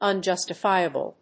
音節un・jus・ti・fi・a・ble 発音記号・読み方
/`ʌndʒˈʌstəfὰɪəbl(米国英語)/